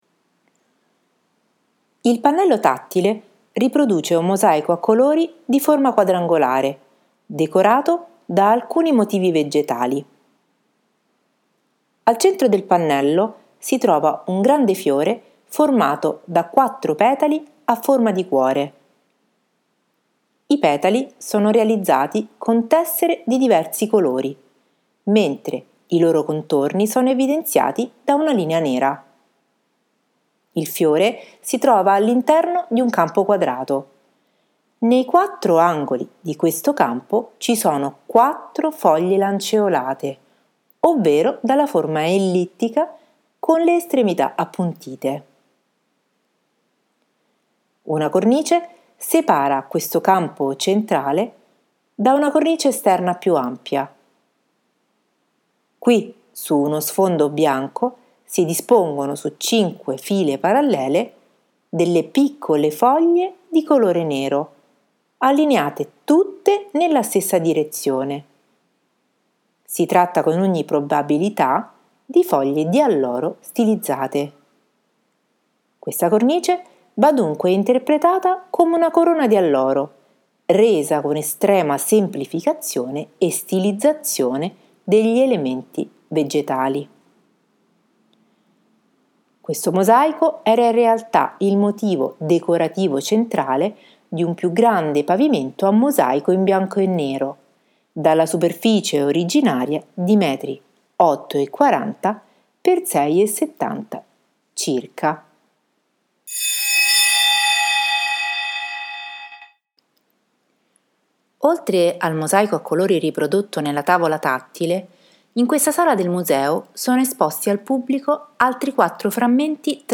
brano audio, un vero e proprio racconto sonoro che arricchisce e completa la percezione del mosaico esposto.
Montemartini_Racconto_Il mosaico della Real Casa.mp3